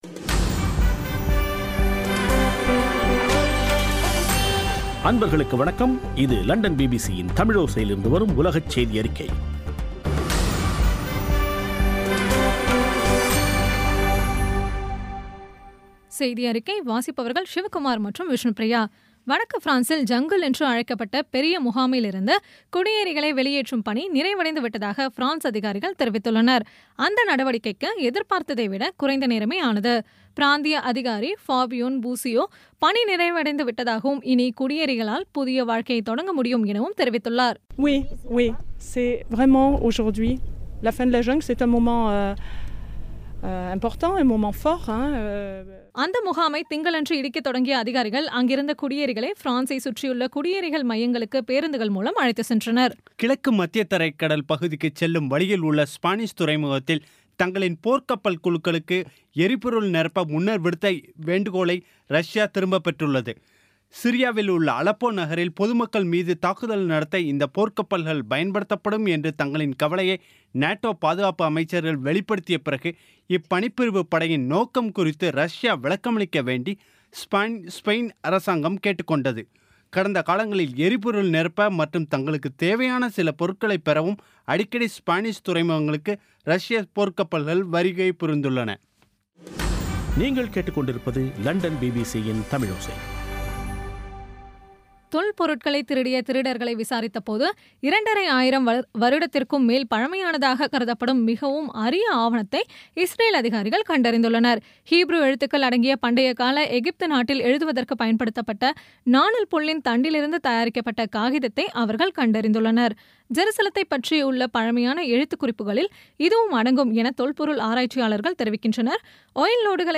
இன்றைய (அக்டோபர் 26ம் தேதி) பிபிசி தமிழோசை செய்தியறிக்கை